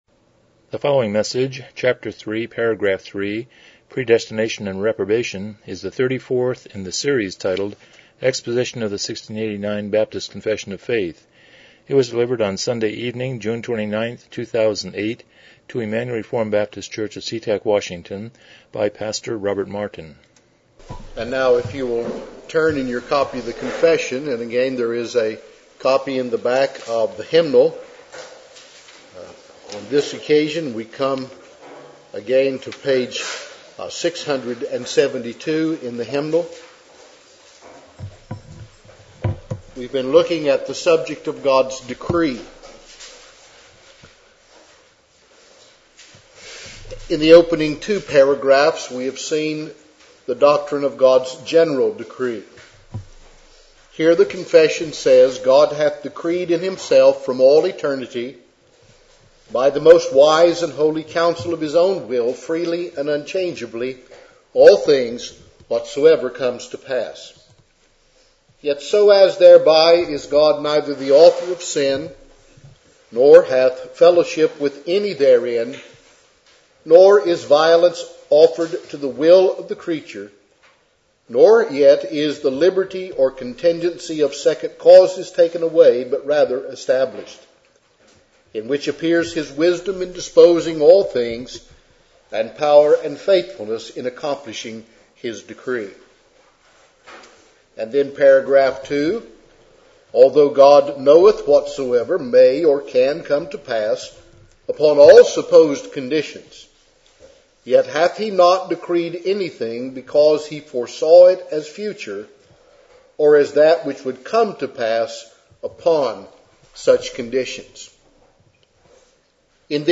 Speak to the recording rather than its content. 1689 Confession of Faith Service Type: Evening Worship « 06 The Uniqueness of Man